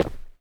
ROCK.3.wav